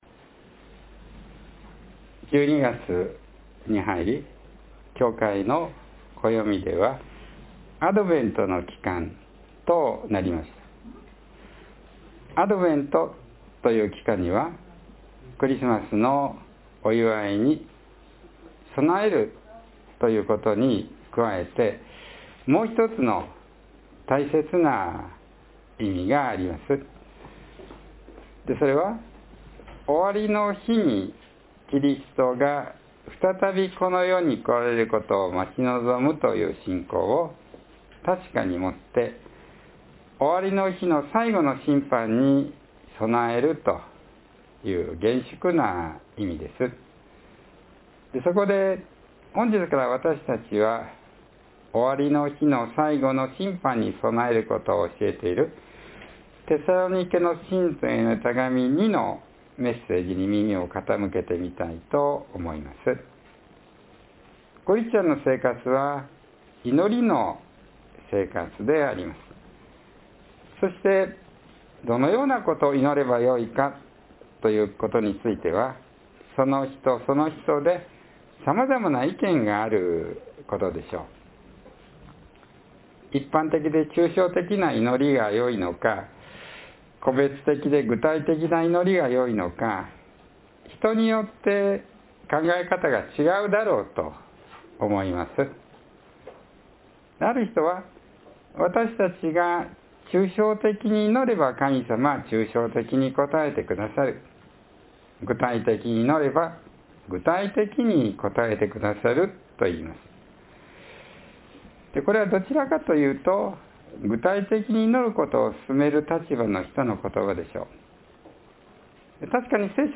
（12月1日の説教より） December has come, and according to the church calendar, it is now the season of Advent.